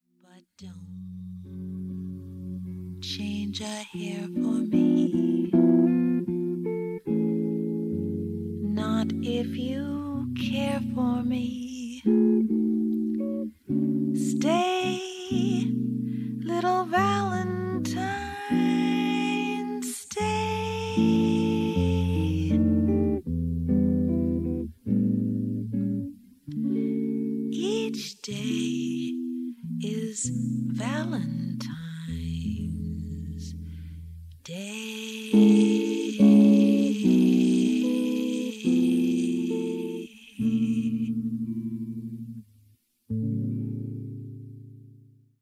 ヴォーカルは決して上手いとは言えない。でも寄り添うギターはその歌声に最大級の理解をもっている。
親密な空気感は親しい間柄だからこそでしょう。